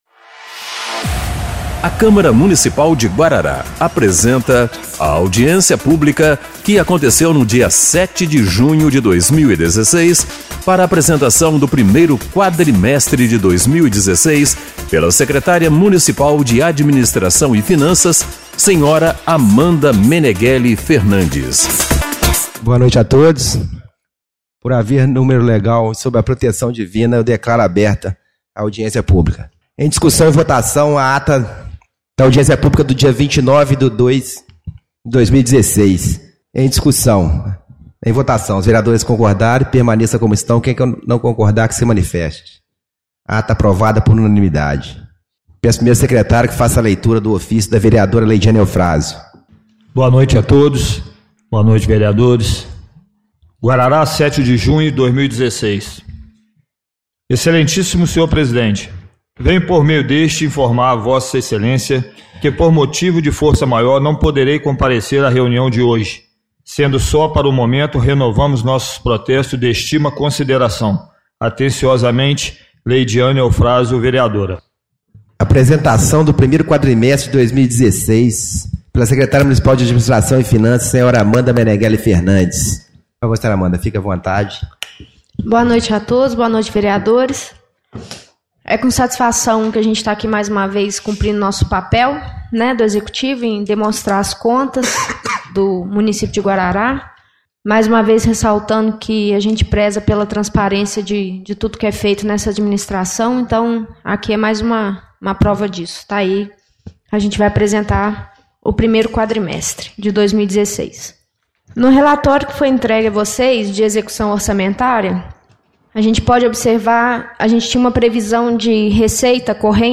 07/06/2016 - Audiência Pública